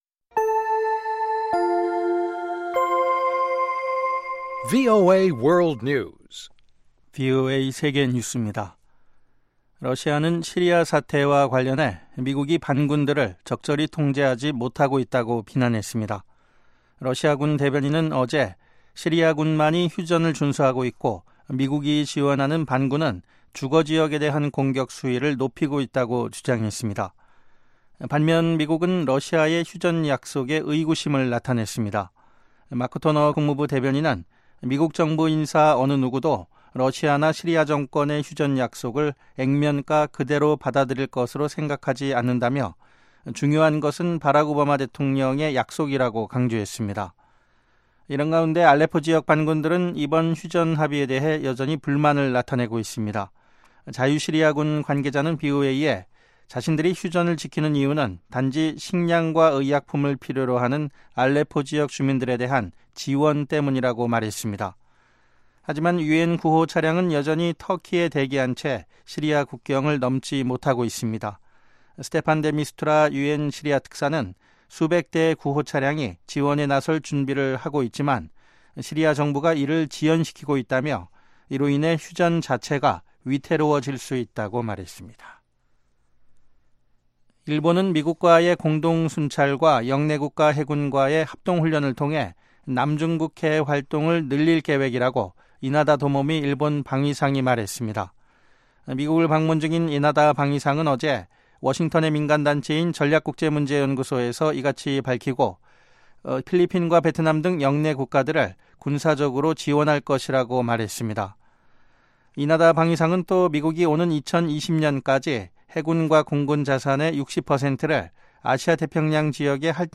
생방송 여기는 워싱턴입니다